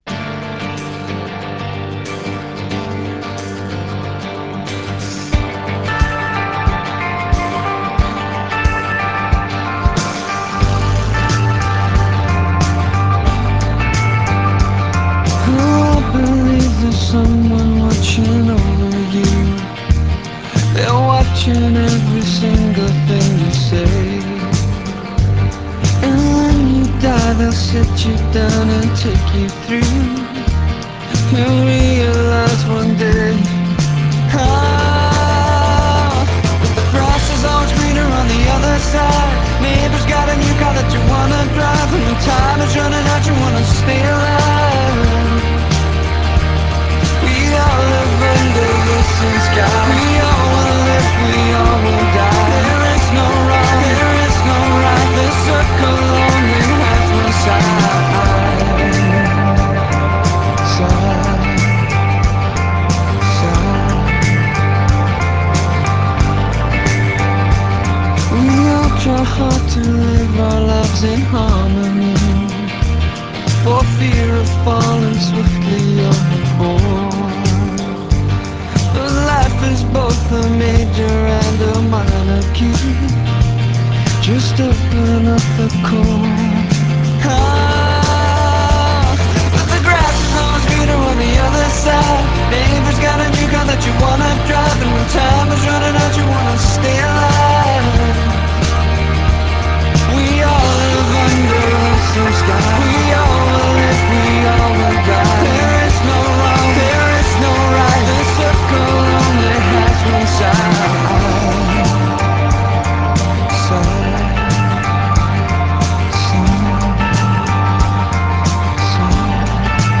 Chanson pop dans la plus belle tradition des années Beatles